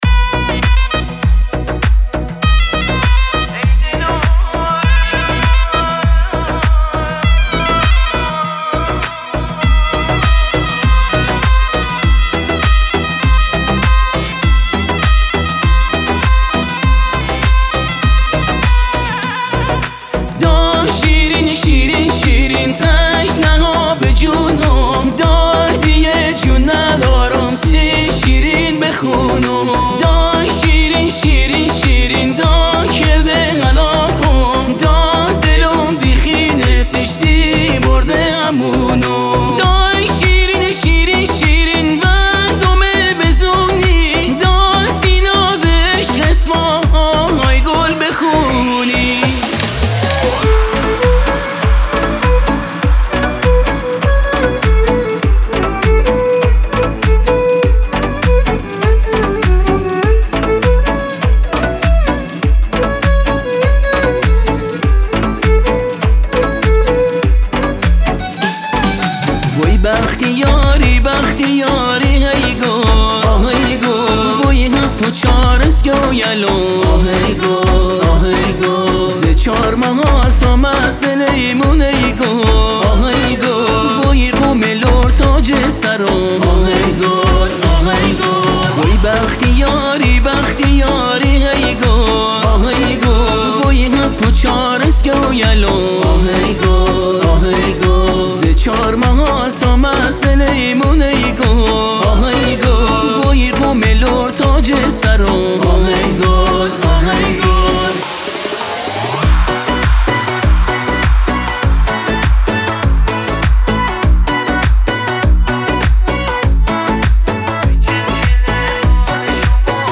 آهنگ بختیاری